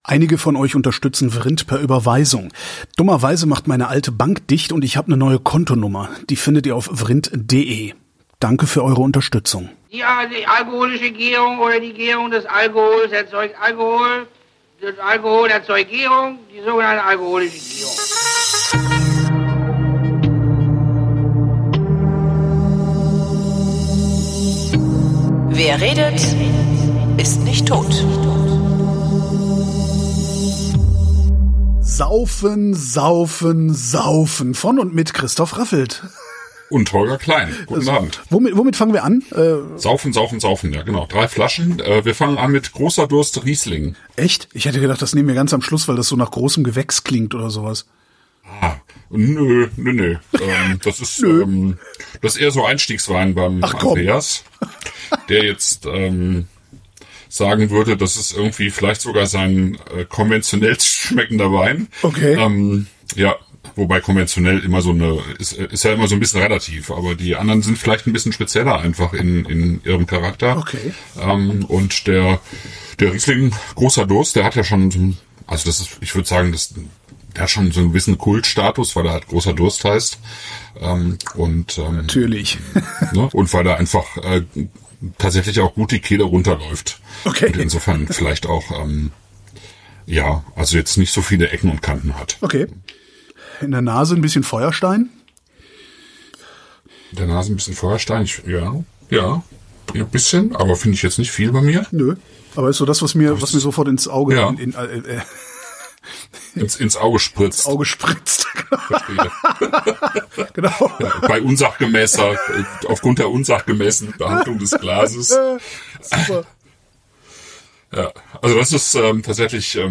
Bitte entschuldigt den schlechten Klang.